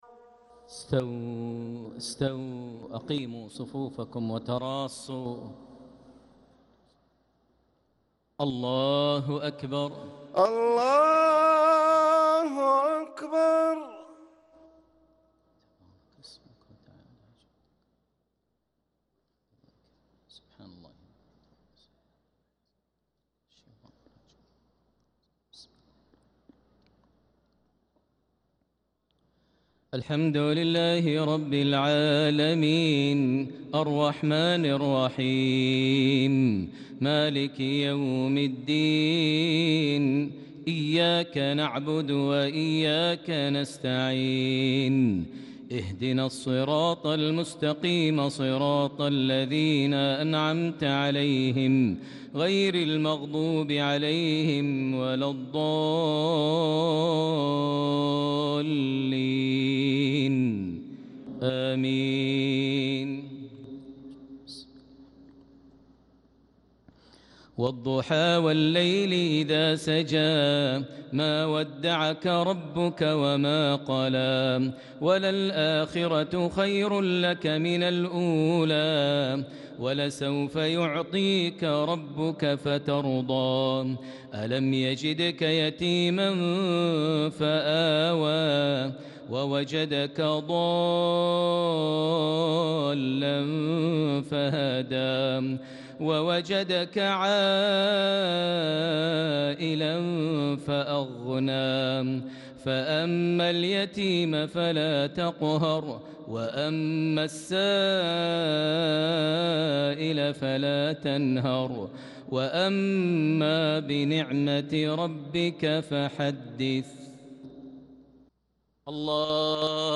صلاة العشاء للقارئ ماهر المعيقلي 5 ذو الحجة 1445 هـ